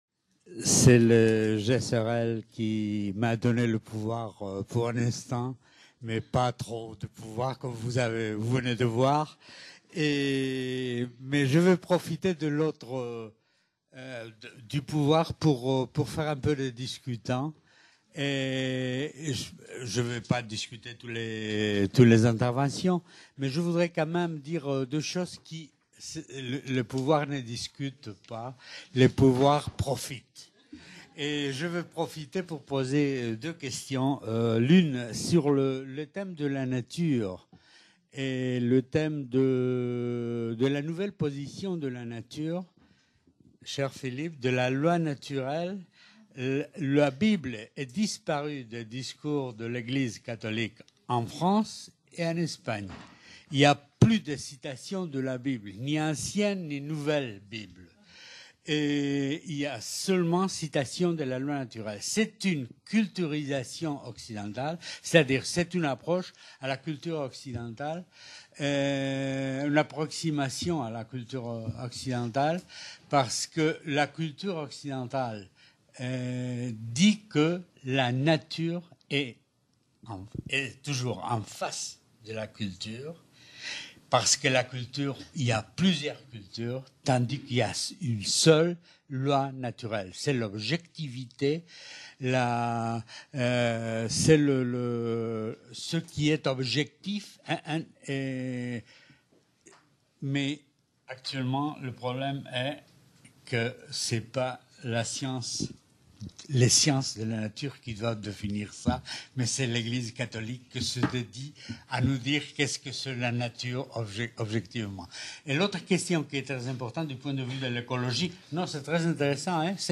15- Recomposition du Religieux en France - Débat | Canal U